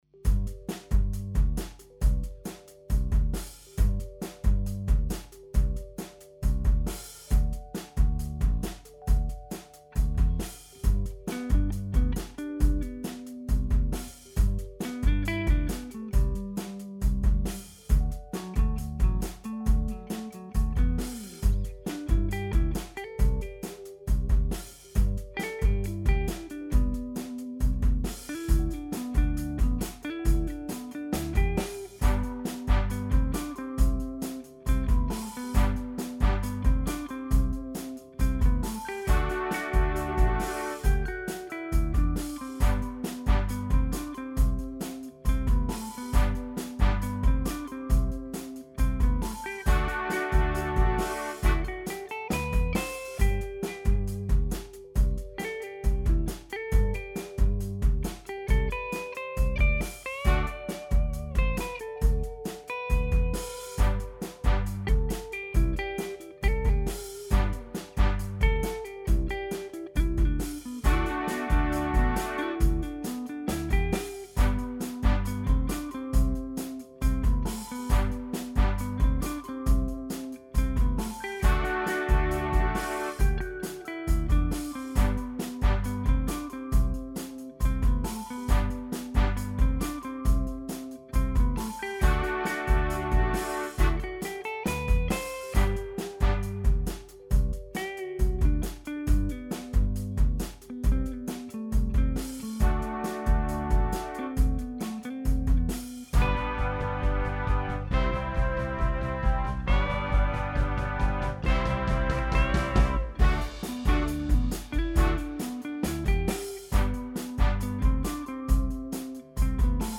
Gitarreninstrumental Demo
Ich schreibe gerade ein Instrumental und habe schon mal ne Demo am Start.
Hat das Schlagzeug zu viele "Open-HiHat-Breaks"?